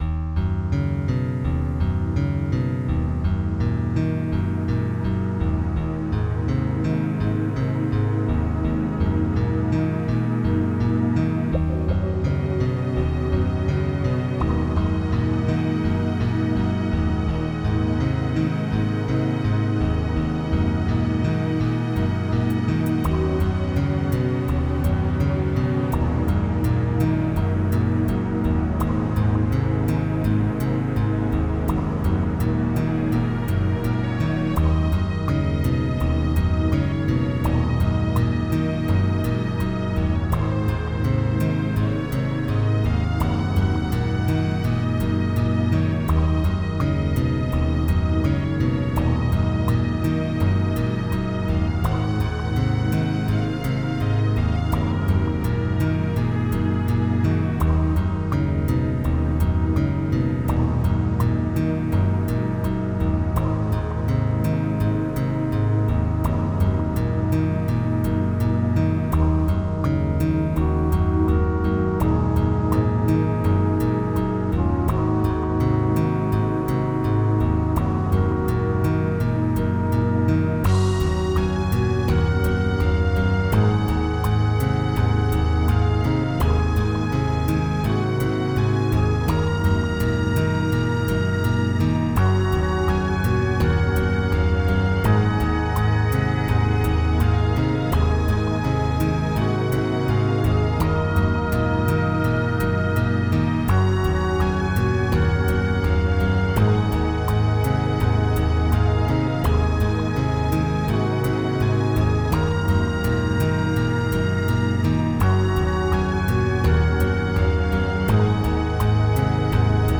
s3m (Scream Tracker 3)
Synth.Flute.Looped
GUSPatch.Trombone
String Piano
Crash Cymbal
PlinkBass
water drops
GUSPatch.AcGuitar
GUSPatch.MarcatoString
Weasel.EchoTrumpet
BigPizzicato.2.Warm